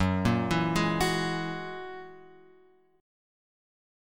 F#9 chord {2 1 2 1 2 x} chord